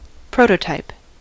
prototype: Wikimedia Commons US English Pronunciations
En-us-prototype.WAV